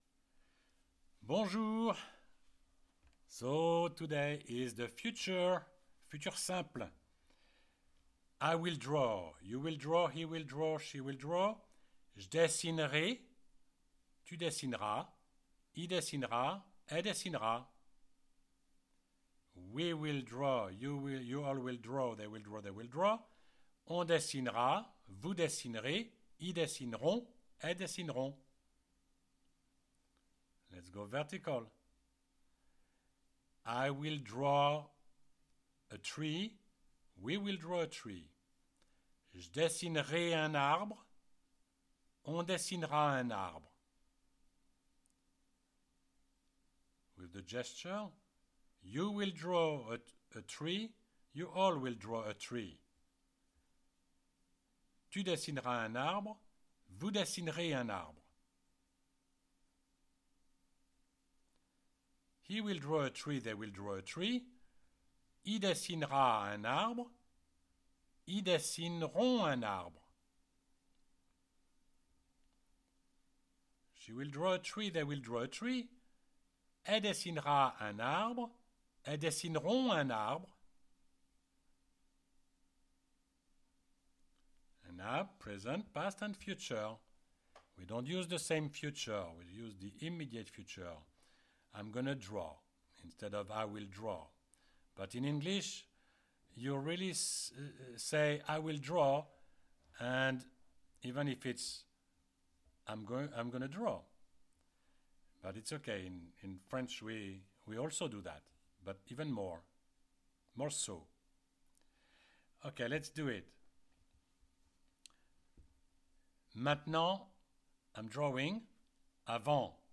CLICK ON THE PLAY BUTTON BELOW TO PRACTICE ‘DESSINER’, ‘TO DRAW’, IN THE FUTURE TENSE
You read and you repeat with the audio.